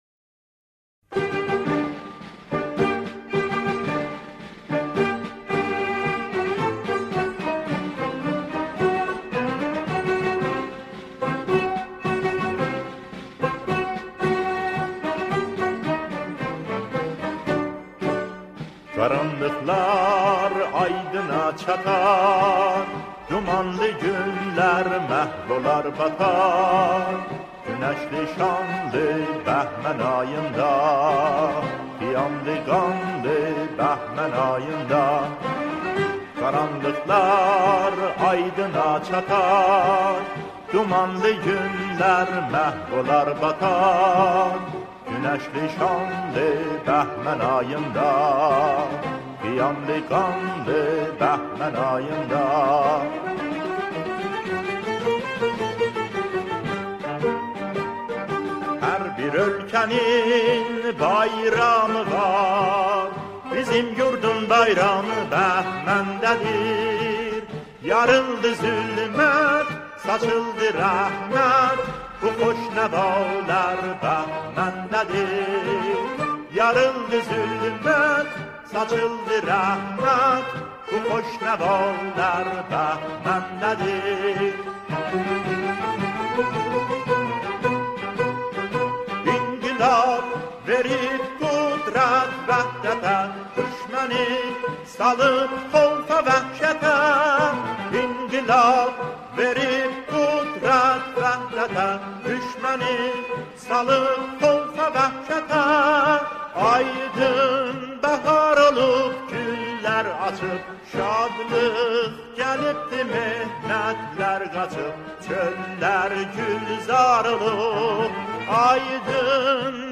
در این قطعه، اعضای گروه، شعری نوستالژی را همخوانی می کنند.